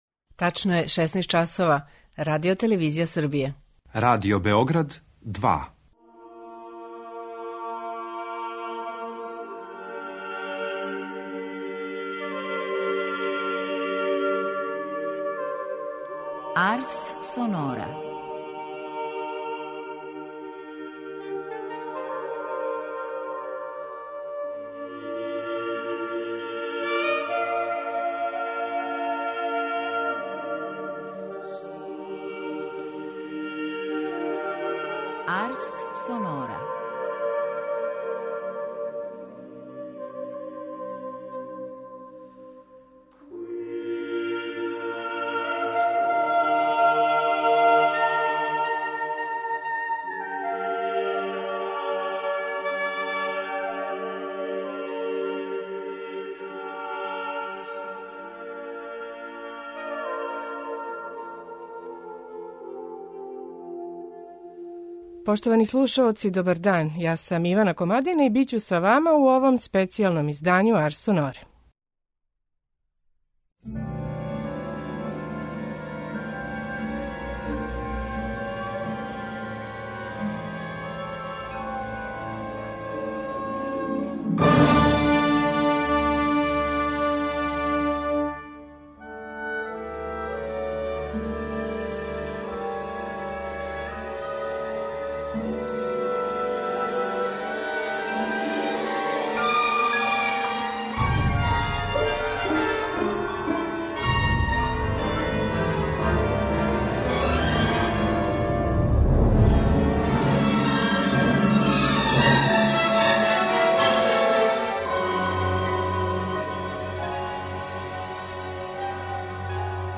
У интерпретацији руско-немачког пијанисте Игора Левита данас слушамо прве две Бетовенове сонате, опус 2 број 1 и 2, посвећене Хајдну, као и прву сонату из опуса 49.